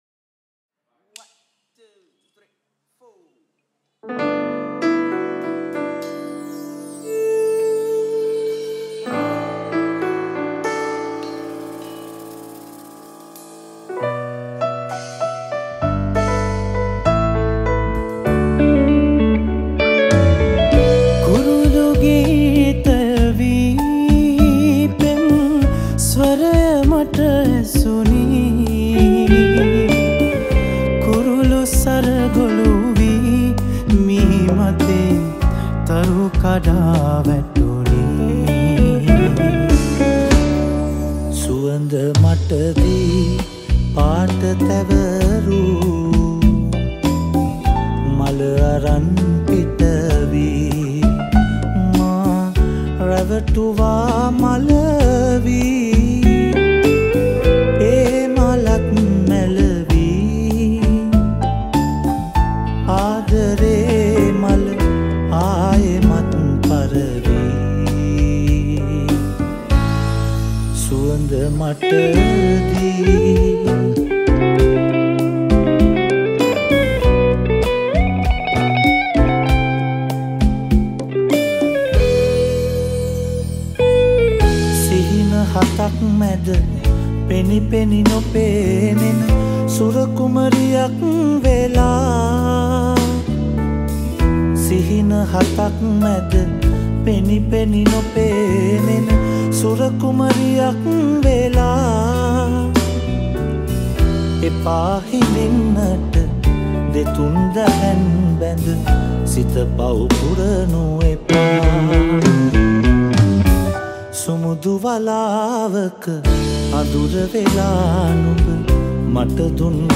Releted Files Of Sinhala Band Medley Songs